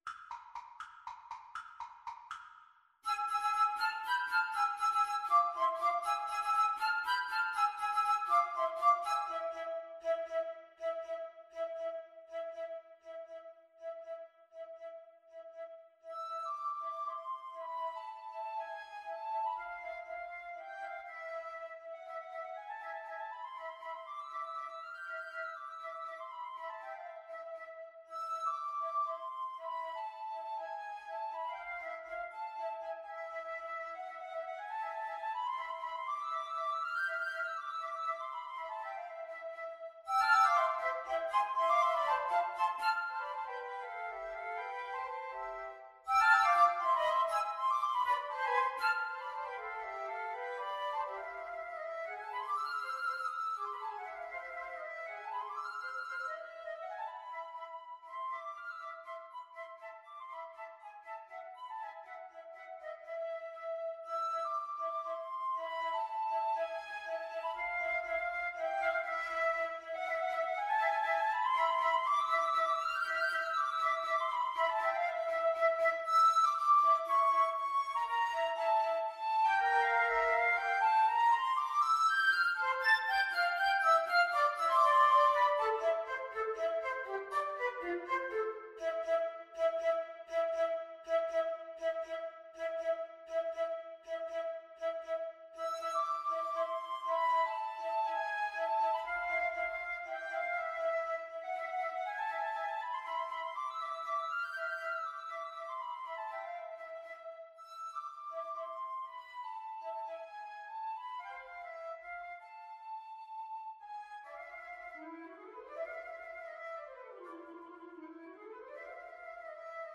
Allegro vivo (.=80) (View more music marked Allegro)